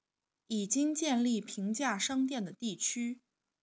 Mandarin_Voiceprint_Recognition_Speech_Data_by_Mobile_Phone